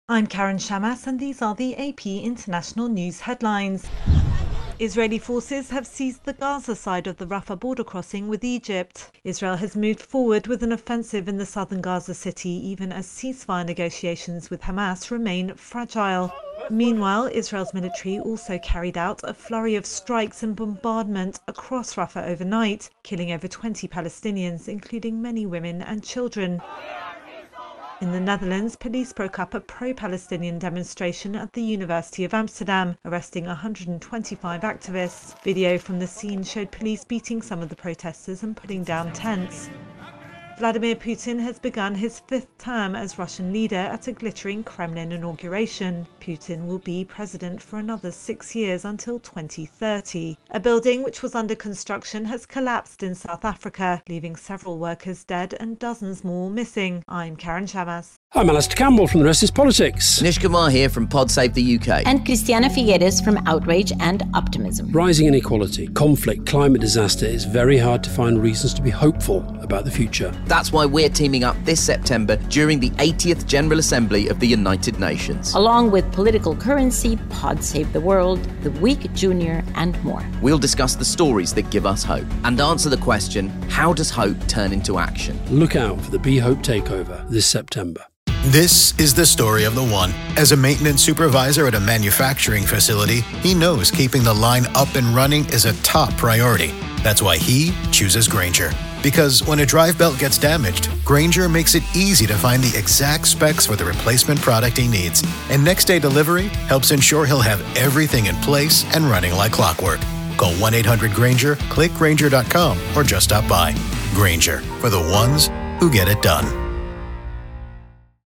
Israel seizes the Gaza side of the Rafah border crossing, police break up Pro-Palestinian protests at the University of Amsterdam, Putin starts his new 6 year term, and a building has collapsed in South Africa. AP correspondent